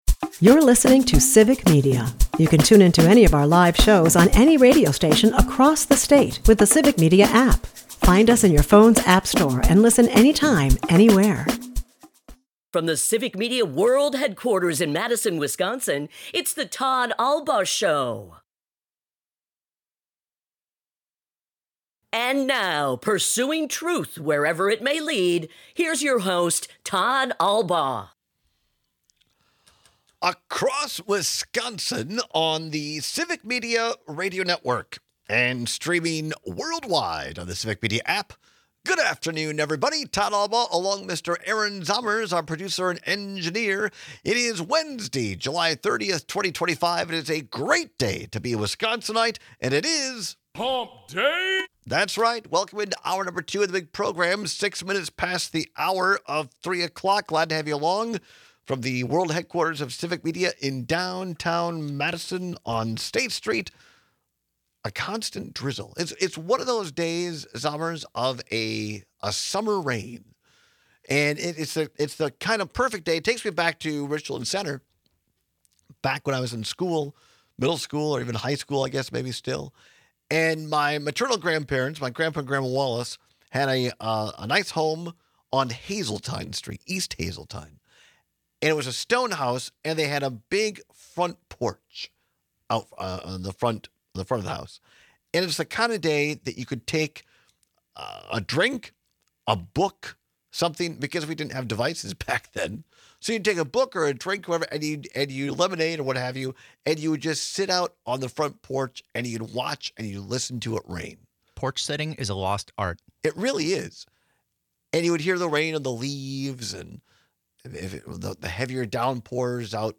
We take some calls and texts on which modern convenience hurts the most when it’s gone.